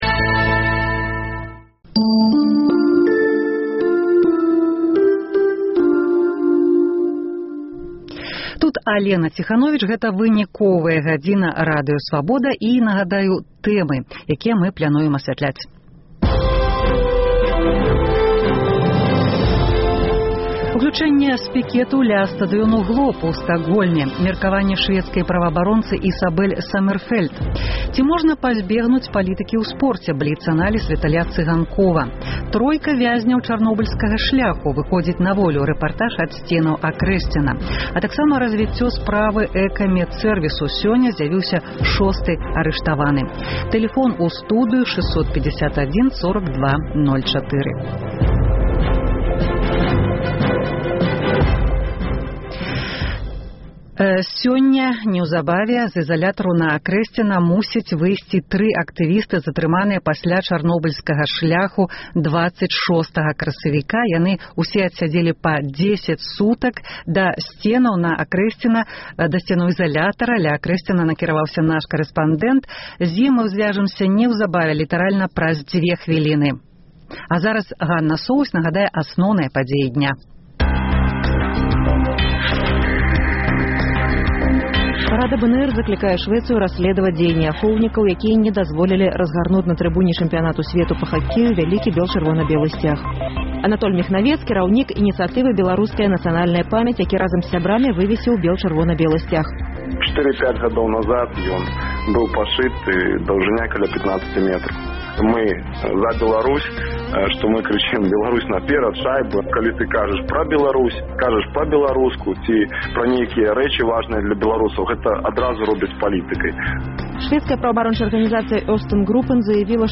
Хакей і бітва за сьцяг: што важней? Уключэньне пікету каля стадыёну Глоб у Стакгольме
Тройка вязьняў Чарнобыльскага шляху выходзіць на волю – рэпартаж ад сьценаў Акрэсьціна. Разьвіцьцё справы «Экамэдсэрвісу».